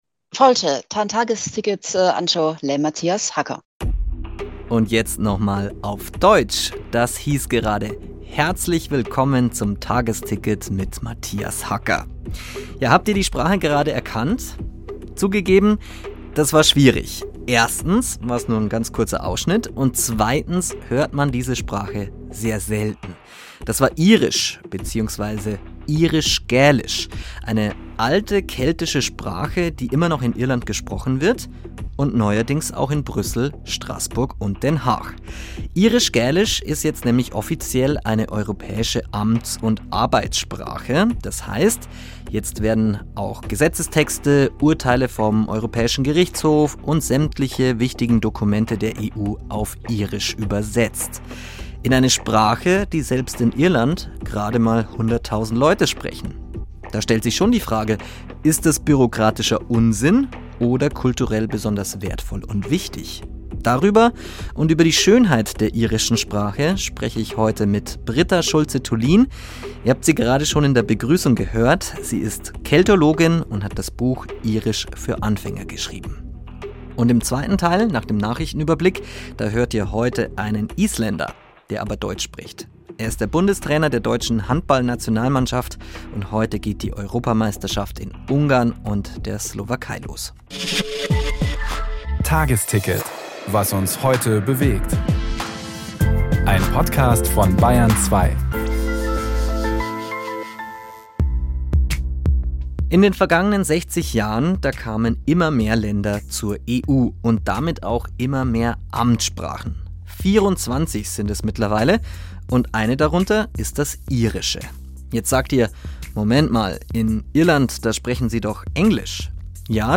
Interview beim Bayrischen Rundfunk